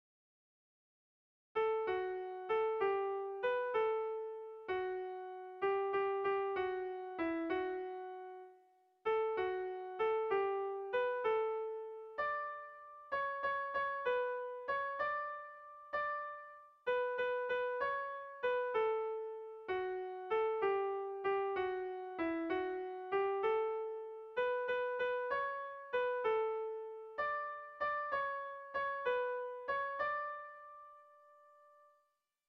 Irrizkoa
Zortziko txikia (hg) / Lau puntuko txikia (ip)
ABDE..